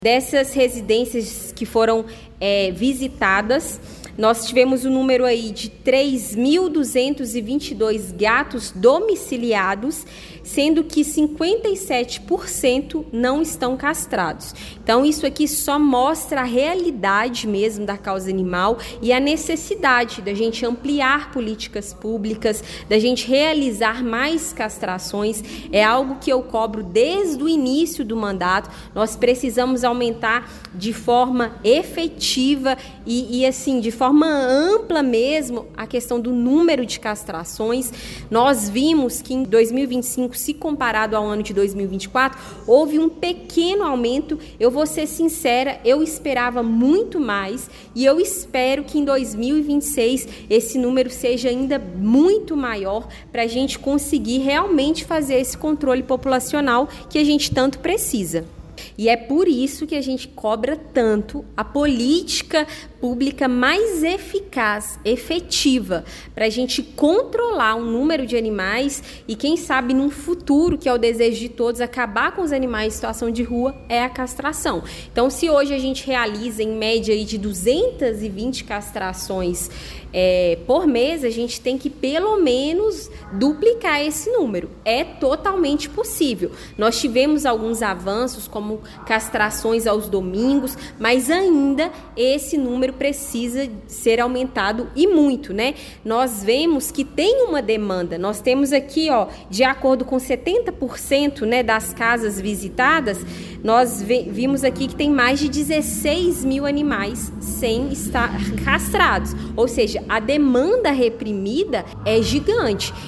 O Portal GRNEWS acompanhou a primeira reunião ordinária da Câmara Municipal em 2026, realizada nesta terça-feira, 20 de janeiro, a vereadora Camila Gonçalves de Araújo (PSDB), a Camila Mão Amiga, apresentou os resultados iniciais da primeira coleta do Censo Municipal de Animais Domésticos.